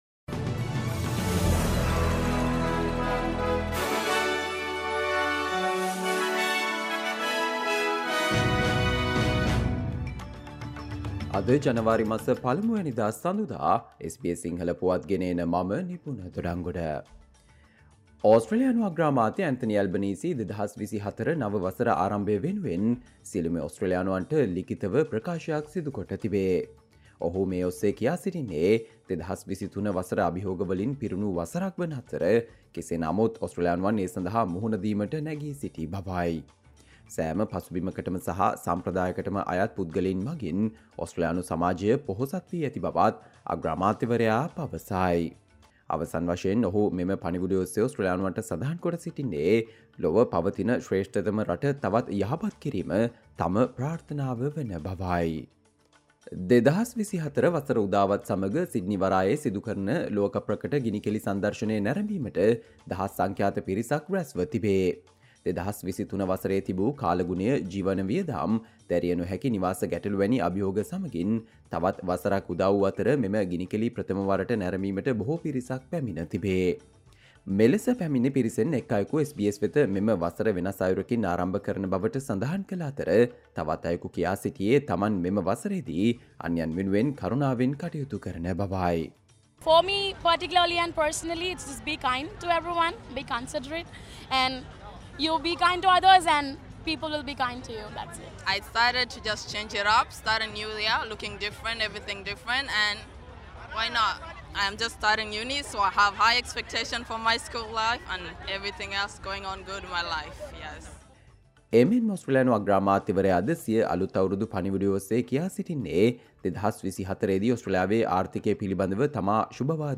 Australia news in Sinhala, foreign and sports news in brief - listen, Monday 01 January 2024 SBS Sinhala Radio News Flash